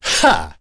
Nicx-Vox_Attack3.wav